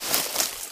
High Quality Footsteps / Bush
STEPS Bush, Walk 09.wav